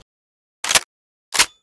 remington870_unjam.ogg